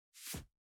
406,パーカーの音,衣服の音,衣類の音,
効果音